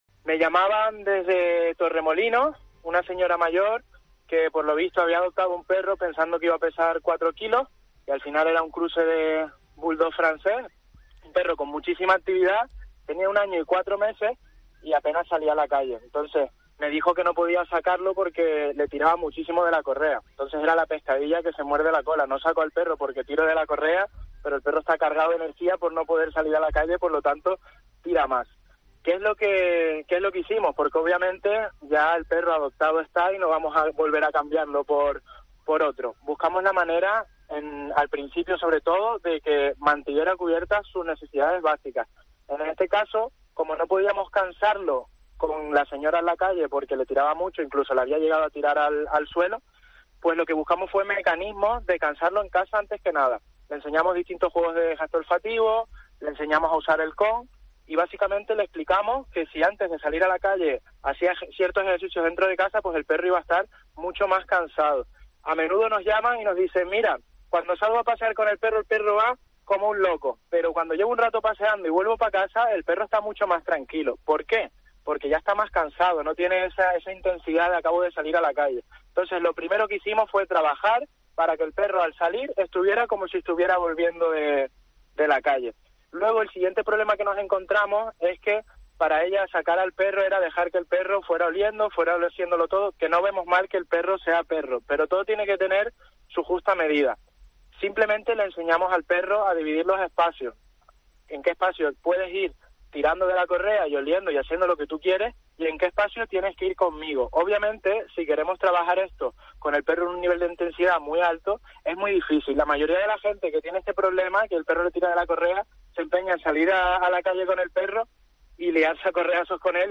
con cierto orgullo en su voz